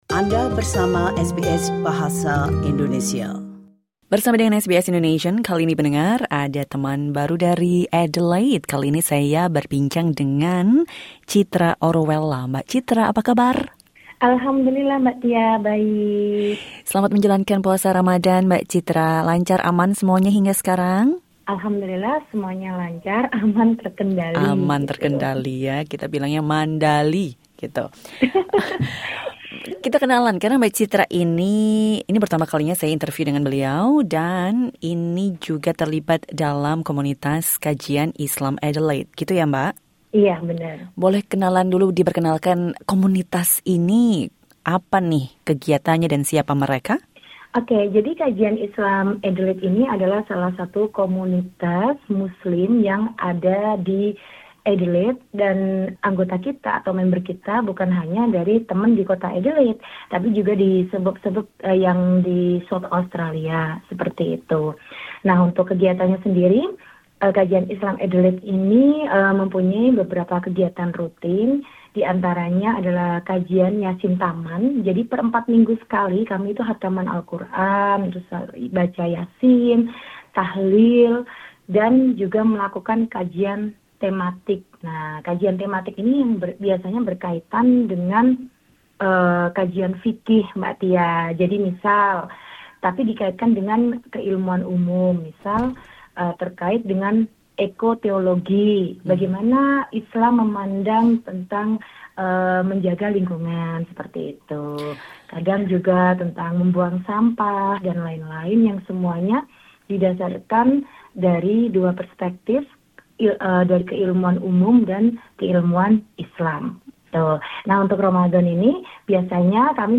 Also listen How this Indonesian Muslim community in Brisbane observes Ramadan SBS Indonesian 12:19 Indonesian Listen to the full interview.